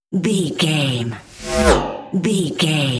Pass by sci fi fast
Sound Effects
Fast
futuristic
pass by
vehicle